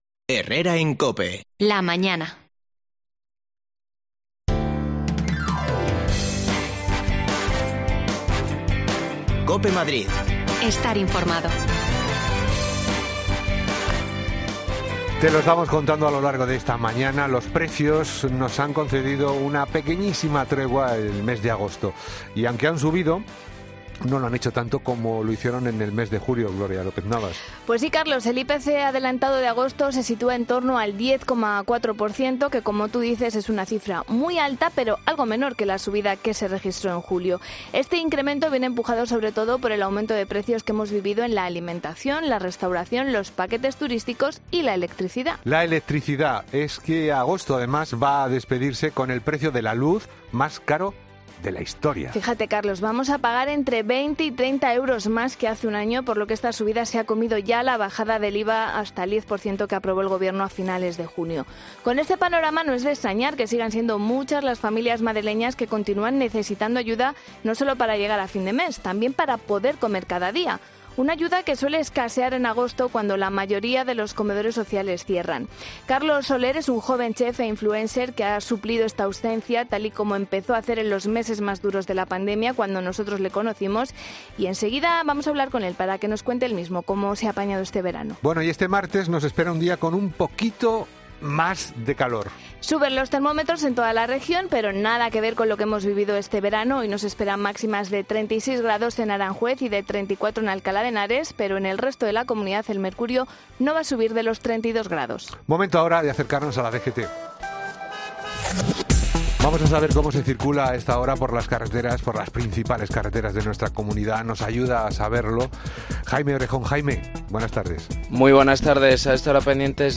La subida del IPC afecta especialmente a las personas con escasos recursos. En Herrera en COPE hemos hablado con un voluntario que se dedica a dar de comer a gente necesitada.
Las desconexiones locales de Madrid son espacios de 10 minutos de duración que se emiten en COPE, de lunes a viernes.